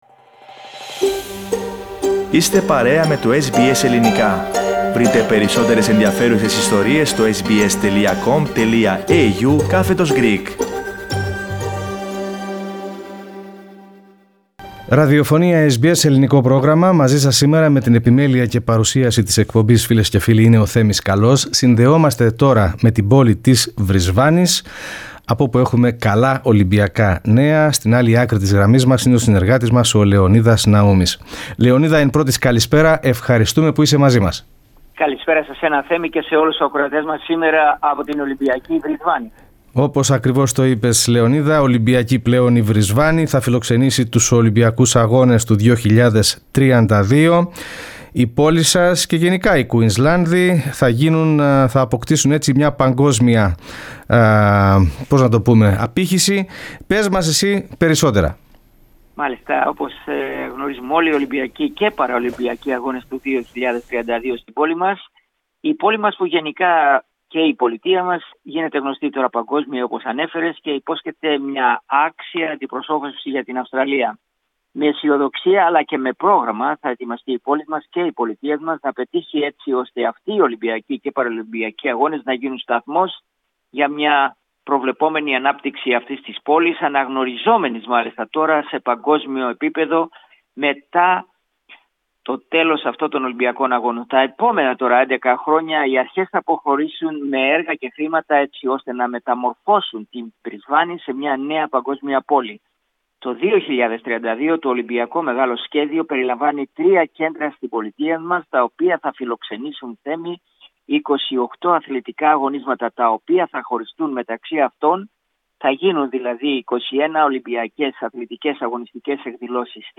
Brisbane